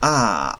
7 a vowel open front unrounded [
open_front_unrounded_vowel.wav